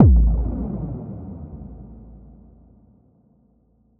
Index of /musicradar/cinematic-drama-samples/Impacts
Impact 06.wav